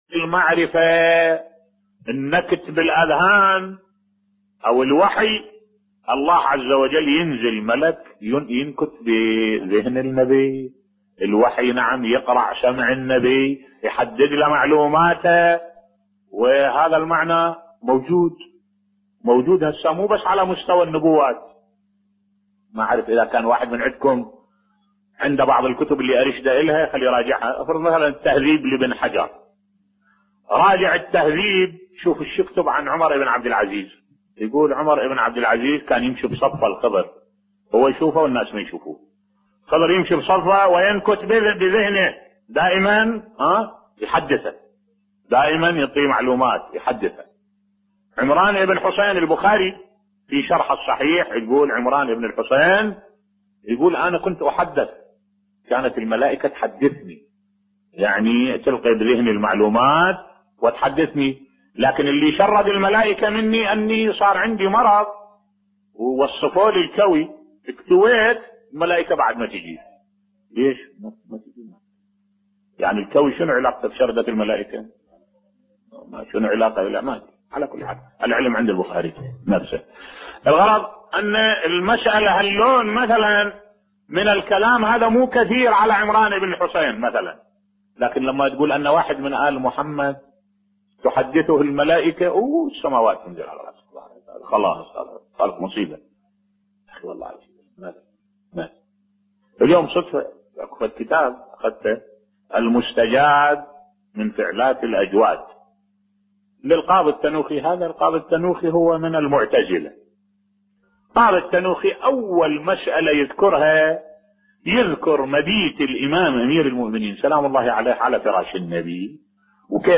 ملف صوتی تحامل محمد كرد علي على فضائل امير المؤمنين (ع) بصوت الشيخ الدكتور أحمد الوائلي